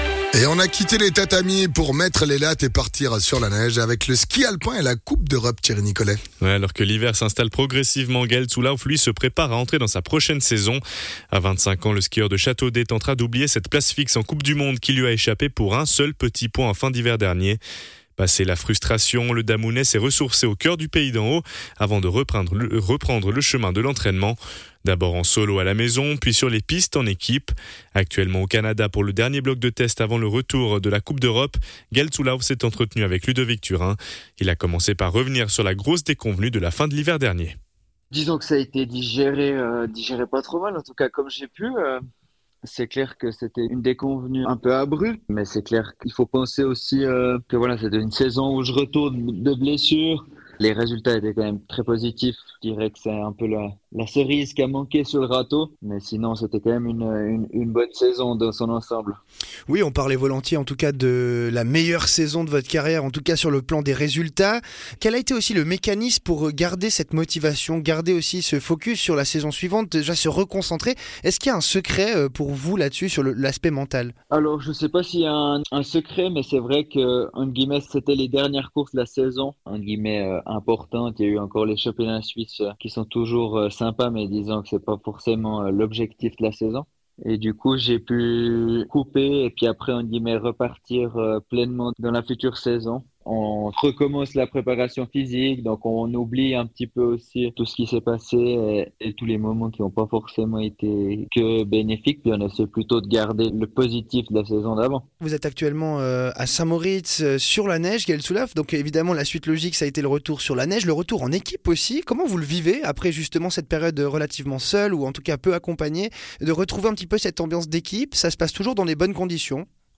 skieur alpin professionnel